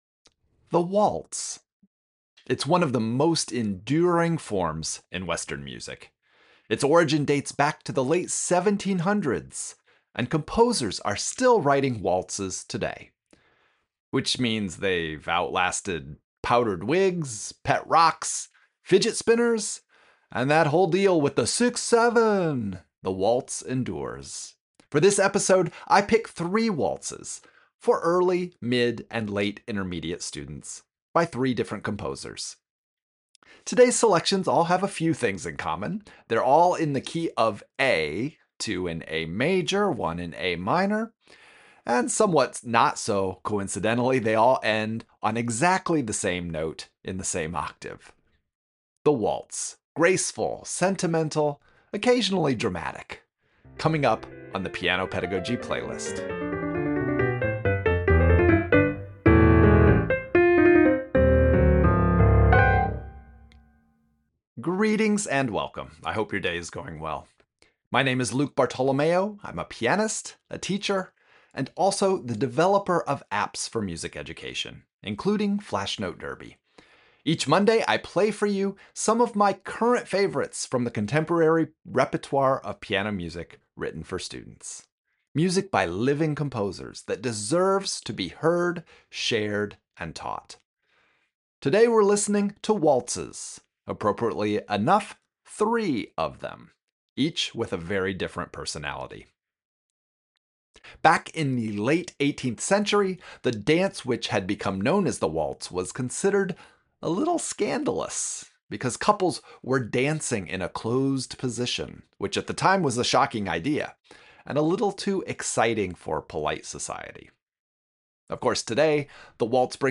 All three happen to be in the key of A, two in major and one in minor, and each one shows a different way this classic dance form continues to live on in today’s teaching repertoire.